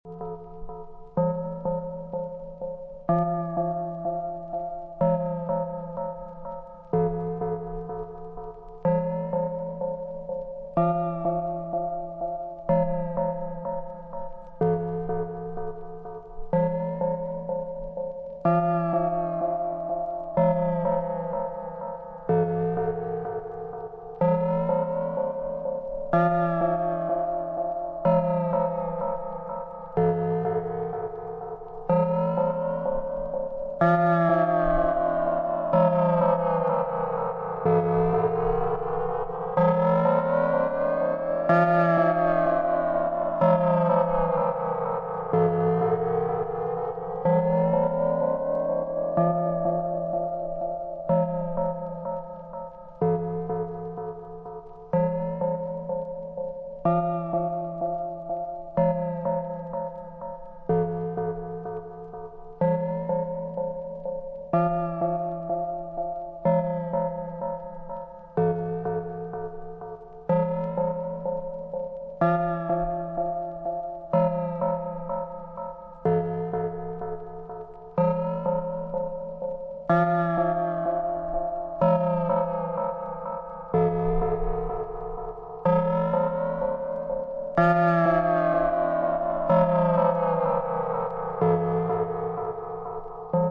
From driving techno to nice modern house tracks